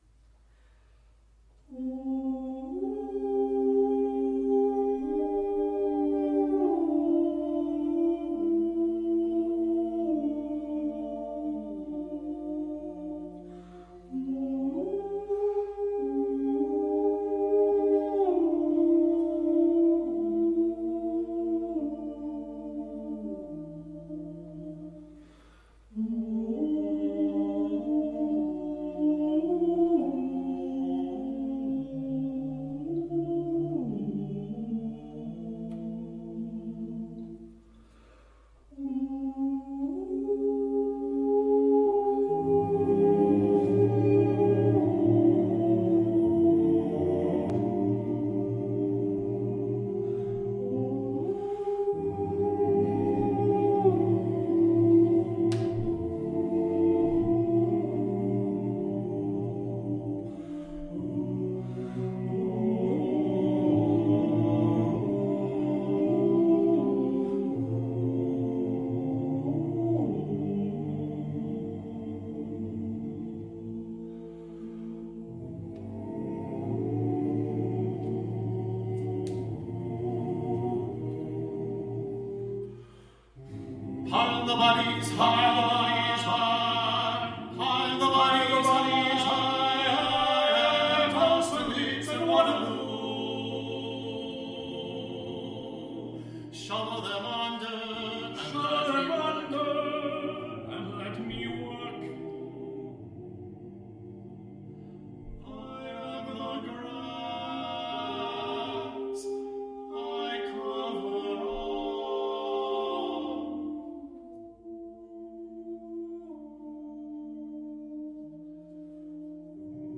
TTBB, TB soli, a cappella